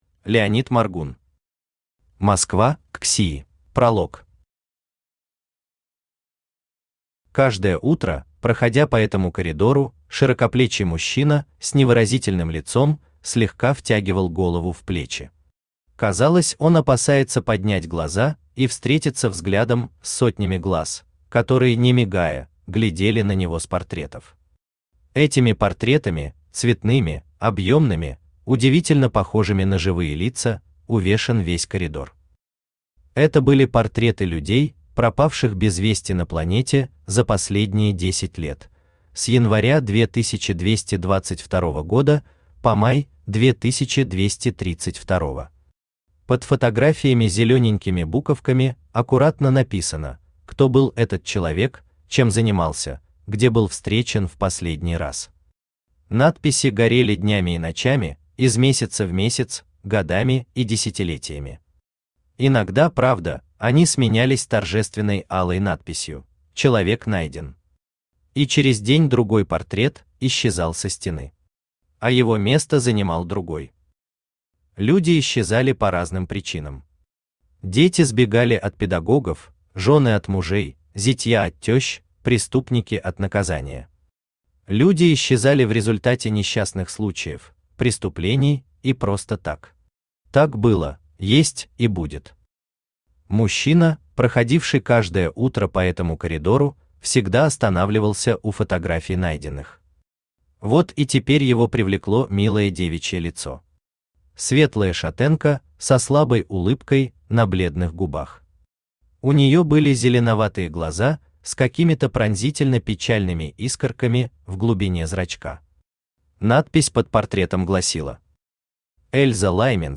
Аудиокнига Москва XXIII | Библиотека аудиокниг
Aудиокнига Москва XXIII Автор Леонид Моргун Читает аудиокнигу Авточтец ЛитРес.